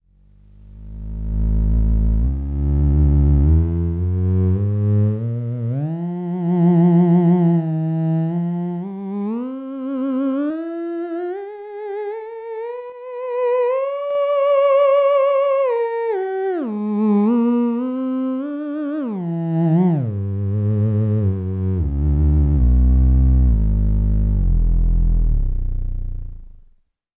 theremin sound  though I call my "original design" theremin like.